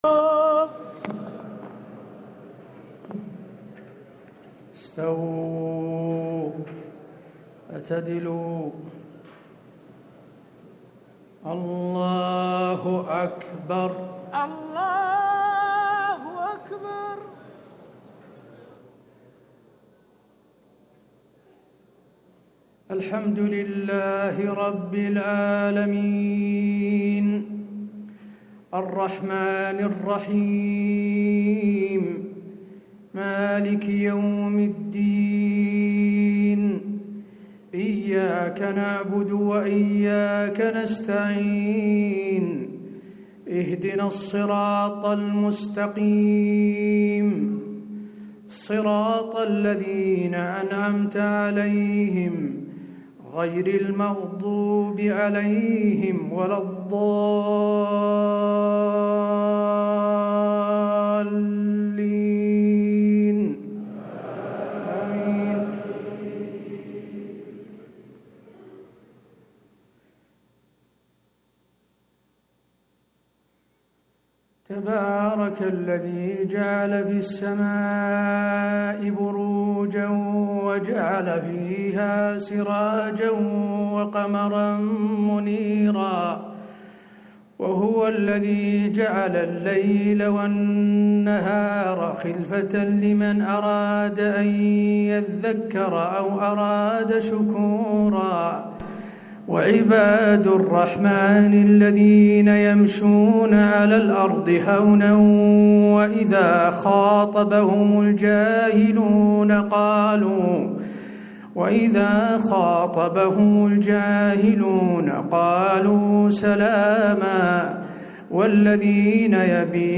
صلاة العشاء 10 رمضان 1437هـ خواتيم سورة الفرقان > 1437 🕌 > الفروض - تلاوات الحرمين